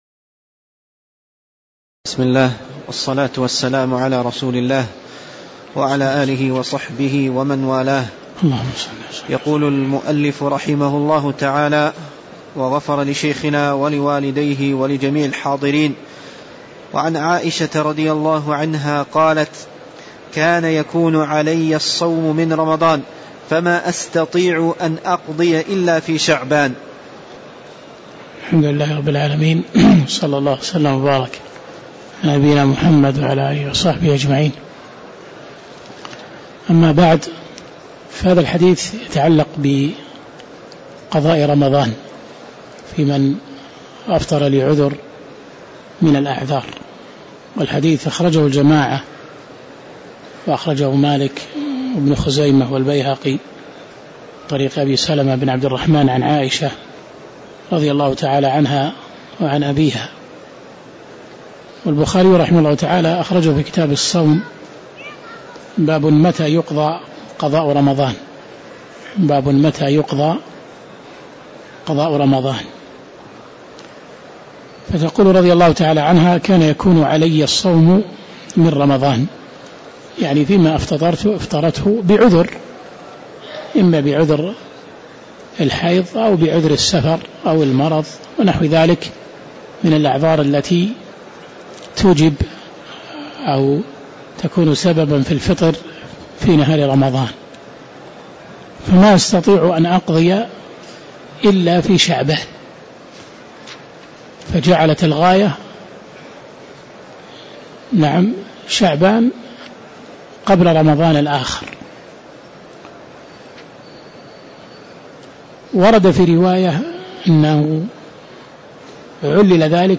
تاريخ النشر ٢٠ رمضان ١٤٣٧ هـ المكان: المسجد النبوي الشيخ